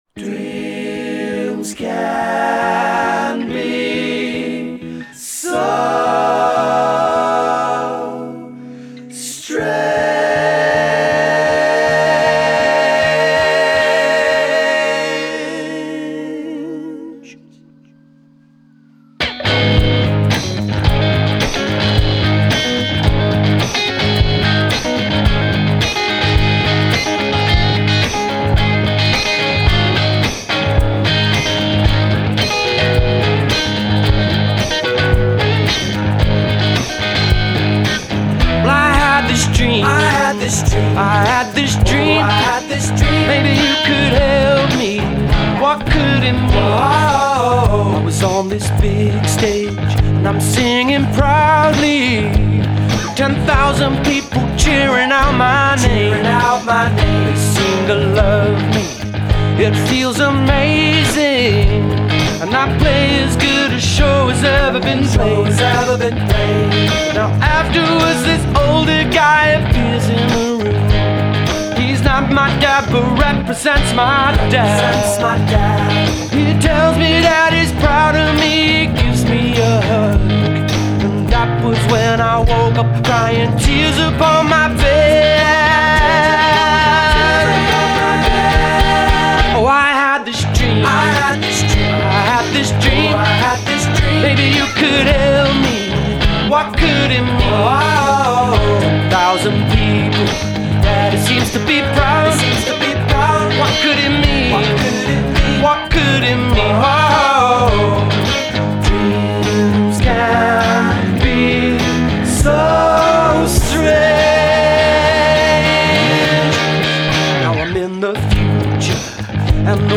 I am pleased to report that it bangs